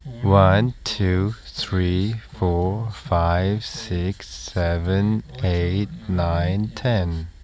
Comparison with other researchers' results: Convolutive Mixtures
first separated signal